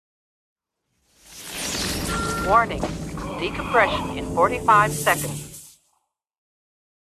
(tosses PADD onto desk) Jonathan Frakes ( William T. Riker ) | Patrick Stewart ( Jean-Luc Picard ) File Updated: 2021-06-14